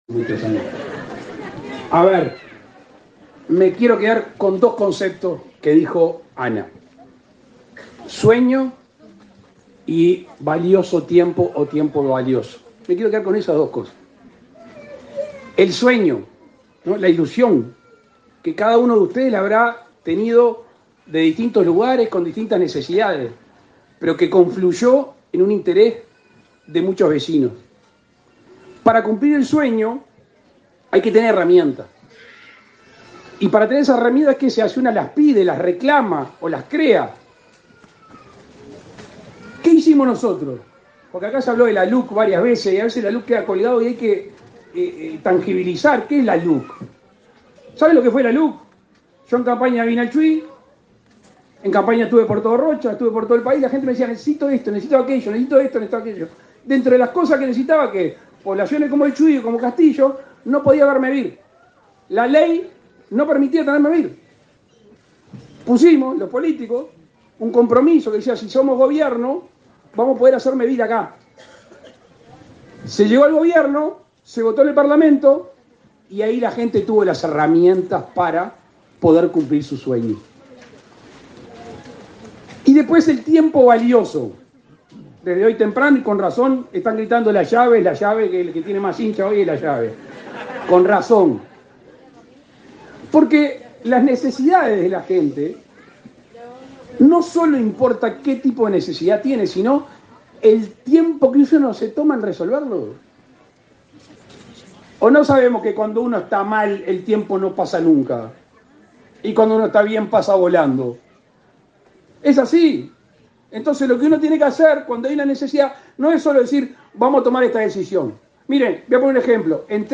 Palabras del presidente de la República, Luis Lacalle Pou
Con la participación del presidente de la República, Luis Lacalle Pou, Mevir inauguró, este 24 de mayo, soluciones habitacionales en Chuy, en el